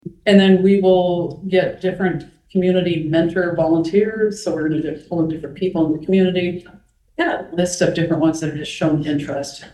Atlantic School Board mtg., 11-13-24